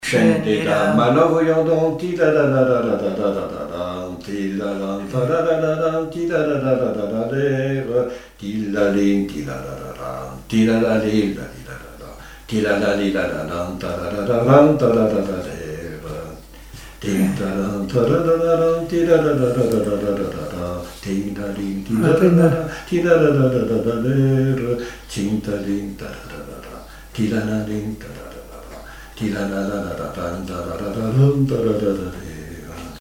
Mémoires et Patrimoines vivants - RaddO est une base de données d'archives iconographiques et sonores.
danse : quadrille : chaîne des dames
Pièce musicale inédite